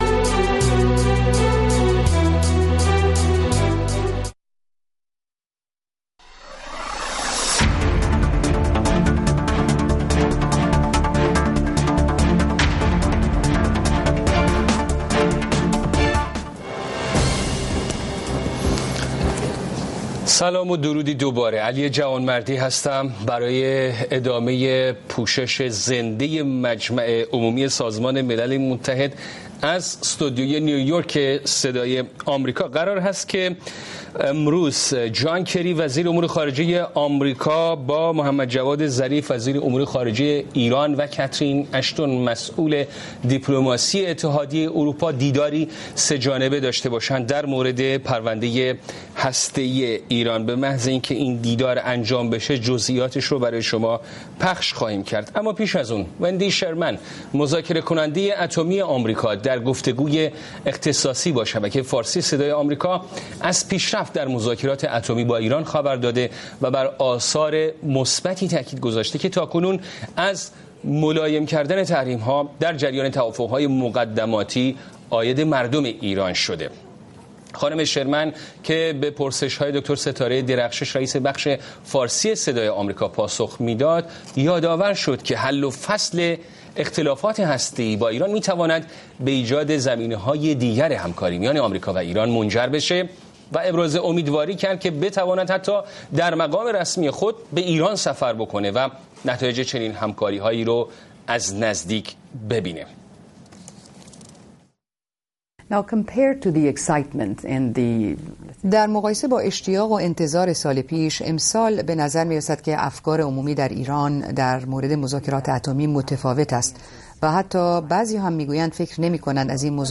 روی خط برنامه ای است برای شنیدن نظرات شما. با همفکری شما هر شب یک موضوع انتخاب می کنیم و شما می توانید از طریق تلفن، اسکایپ، فیس بوک یا ایمیل، به صورت زنده در بحث ما شرکت کنید.